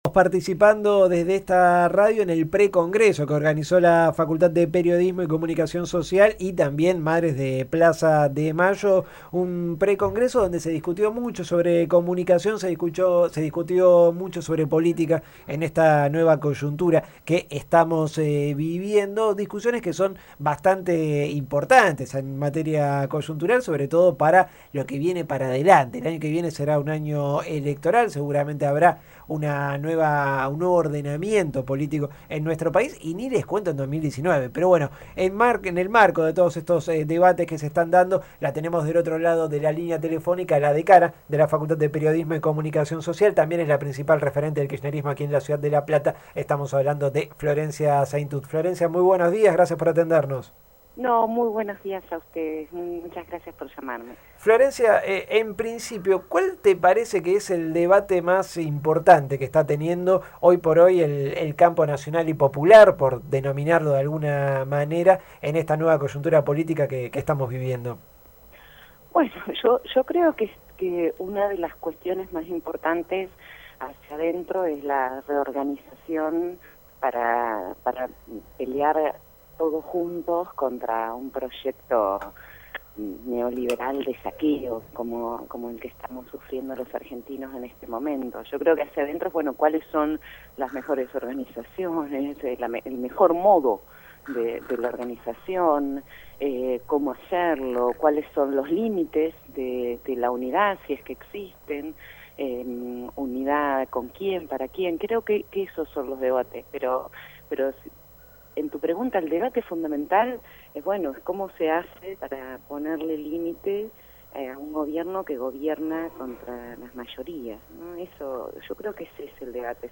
(14/09/16) Luego de la jornada del III Pre Congreso de Periodismo y Comunicación de la Asociación Madres de Plaza de Mayo realizada en la Facultad de Periodismo y Comunicación Social, Florencia Saintout, dialogó con Caídos del Catre.